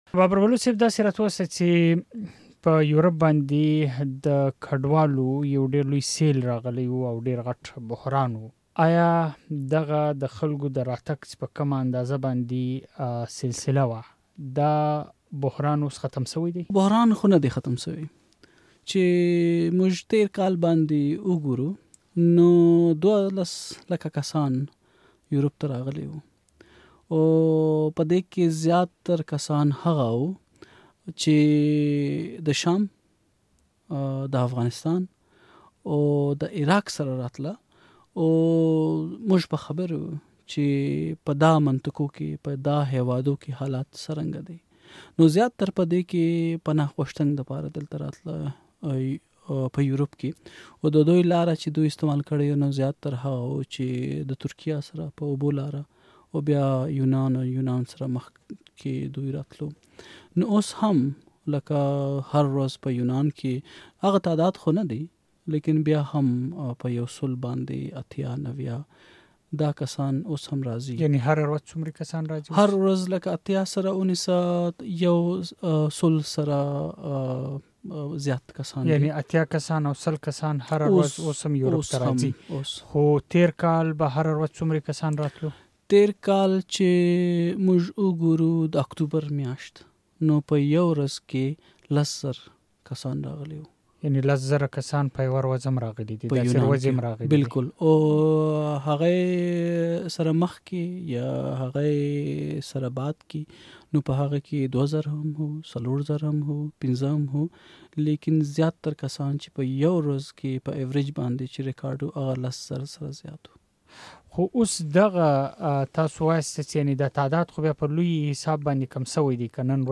د مشال مرکه کې د کډوالو بحران، د هغو ژوند، تمې، نا هيلۍ، د اروپايي هېوادونو غبرګون، د کډوالۍ لاملونه او د قاچاقبرانو رول ته کتنه شوې ده.